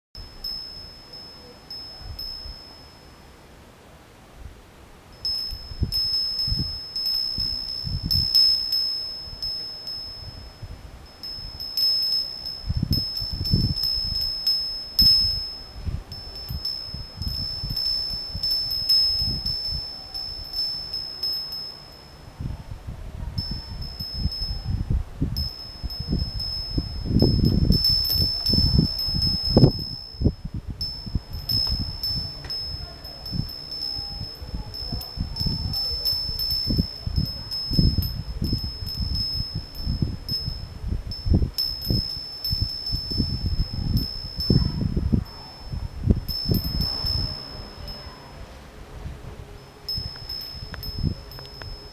◆音で、涼しさを呼ぶ ～豊里中に響く「風鈴の音」～
豊里中・風鈴の音.mp3
涼しさを呼ぶ風鈴（豊里中）